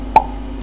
pop.wav